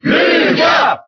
Category:Lucas (SSBB) Category:Crowd cheers (SSBB) You cannot overwrite this file.
Lucas_Cheer_French_SSBB.ogg.mp3